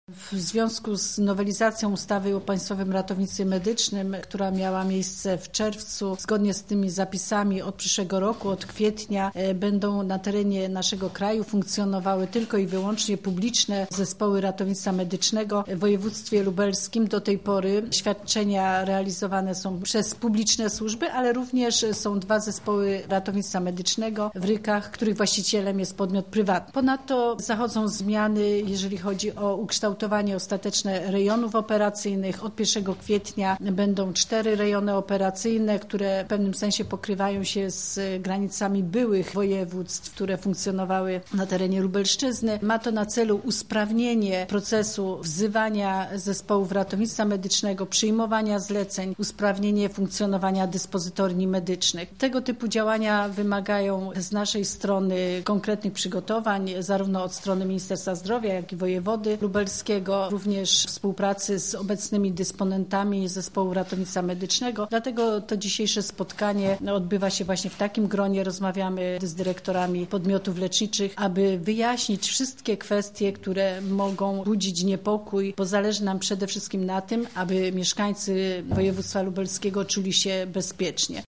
O tym drugim mówi Sekretarz stanu w Ministerstwie Zdrowia, Józefa Szczurek-Żelazko: